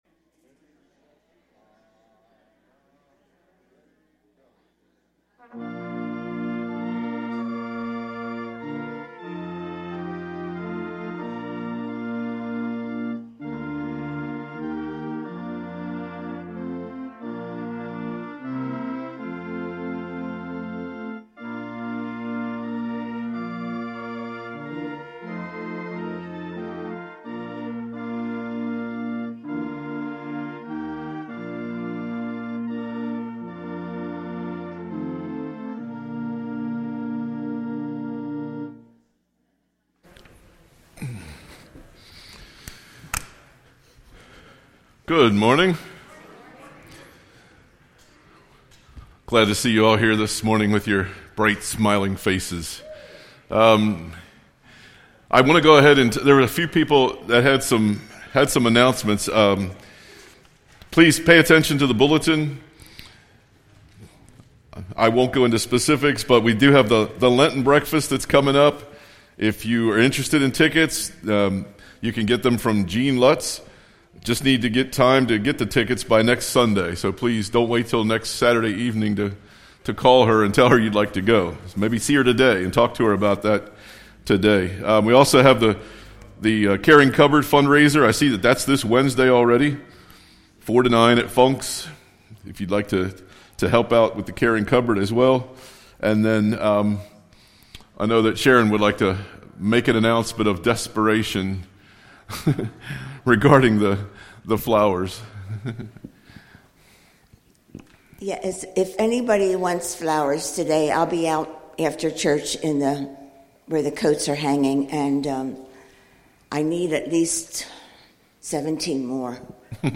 Sunday Worship March 3, 2024